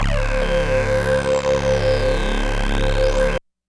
synth03.wav